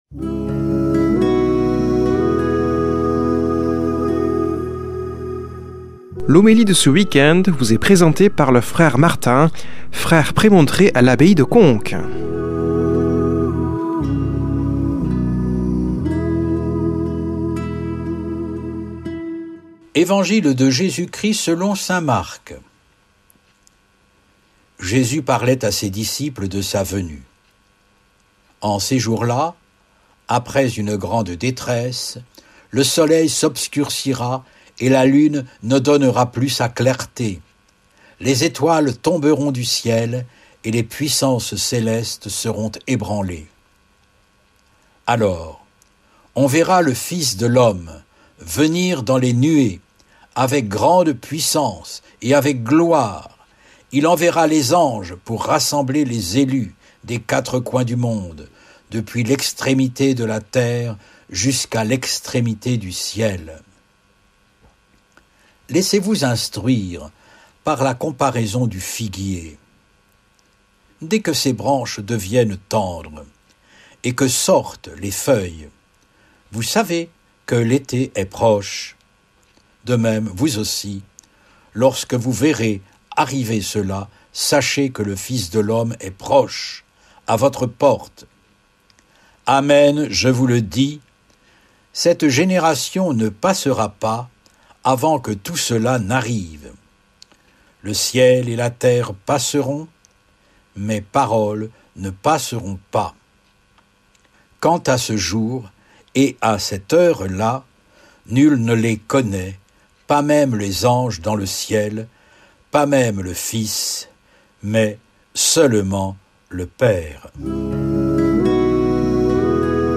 Homélie du 09 nov.
Présentateur